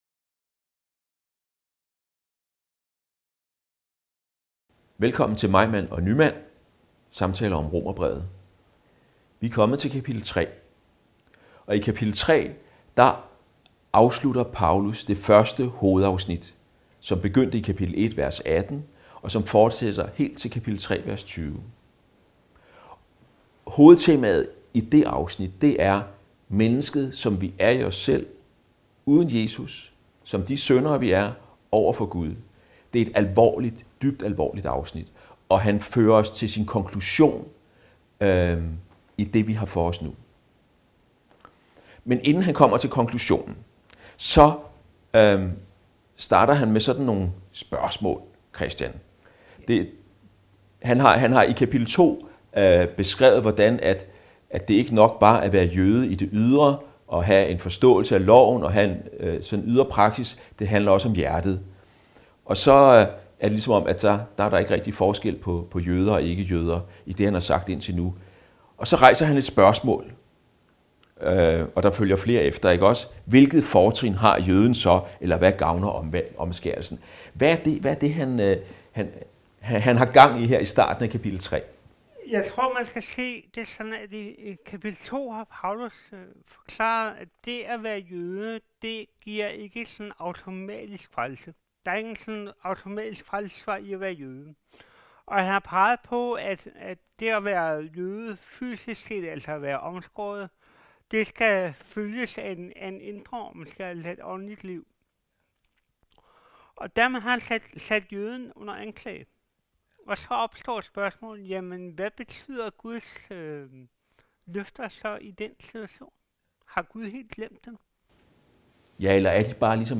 Afspil undervisning